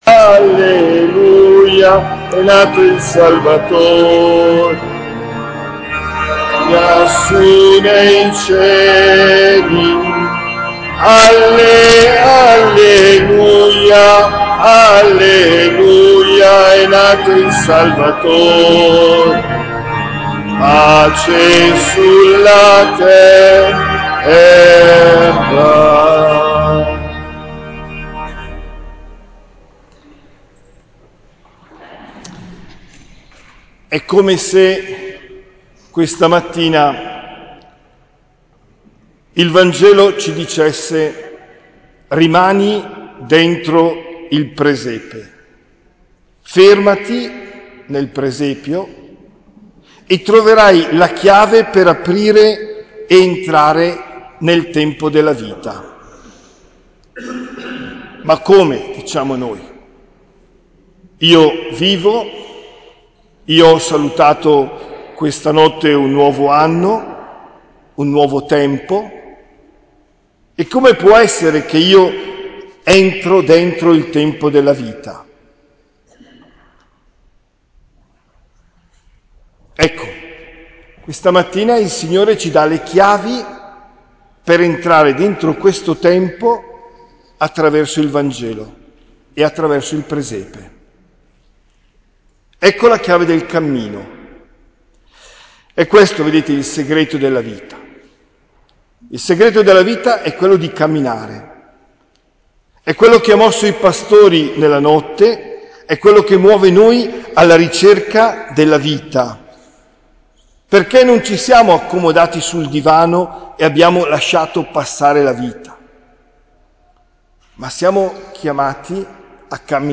OMELIA DEL 01 GENNAIO 2023
omelia-primo-dell-anno.mp3